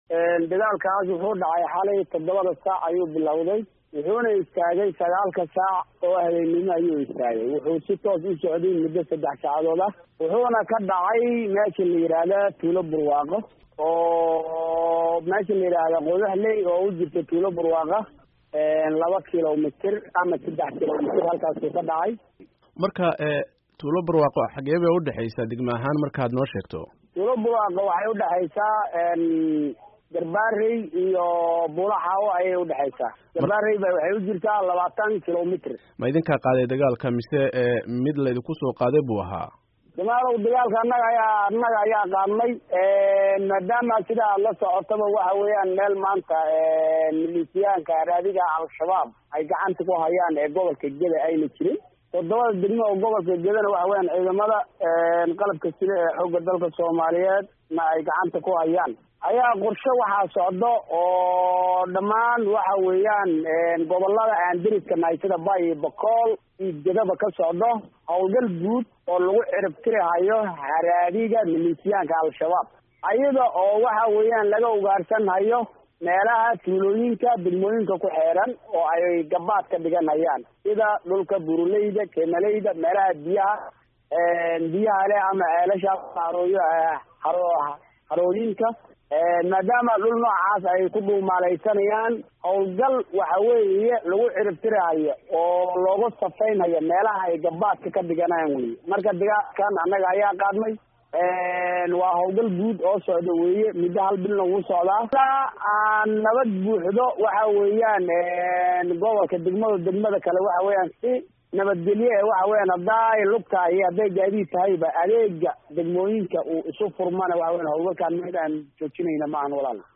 Wareysi: Dagaalka Gedo